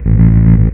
FINGERBSS3-R.wav